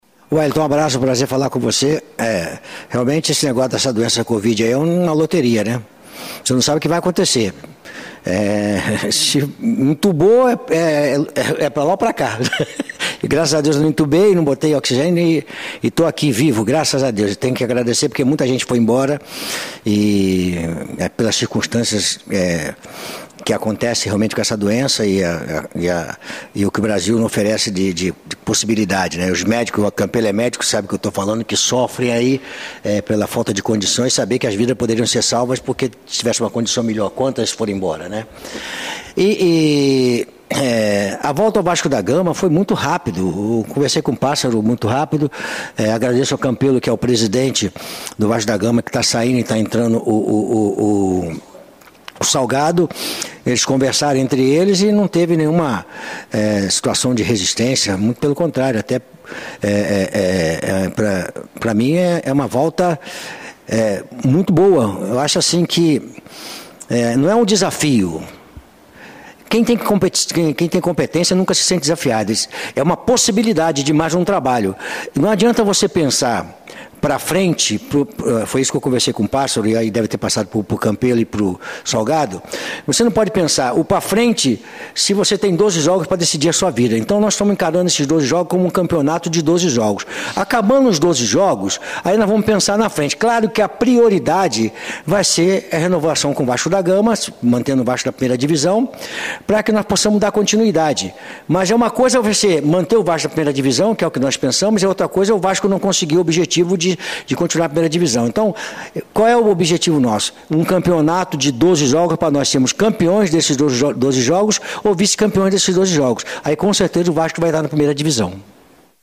Na sua primeira entrevista coletiva nesta segunda-feira (4/1), Luxemburgo deixou claro que para ele é um campeonato de 12 rodadas e quer ser campeão com resultados que deixariam o clube na Série A.